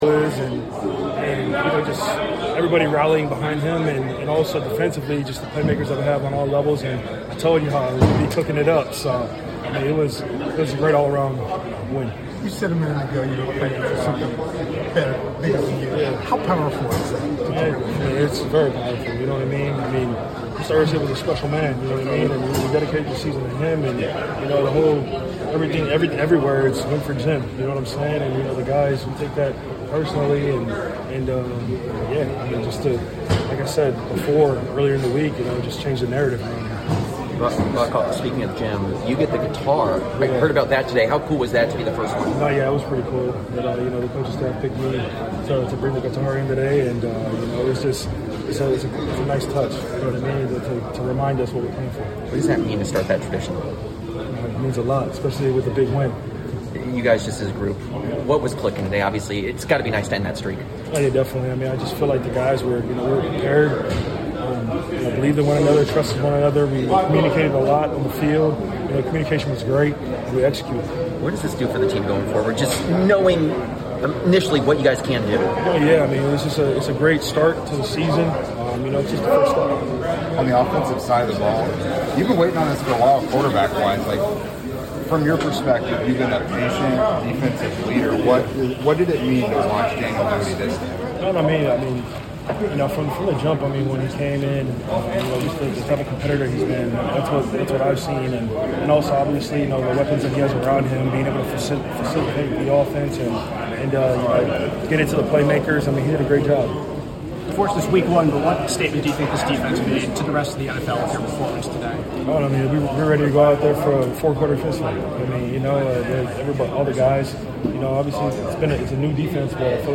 Indianapolis Colts Defensive Lineman DeForest Buckner Postgame Interview after defeating the Miami Dolphins at Lucas Oil Stadium.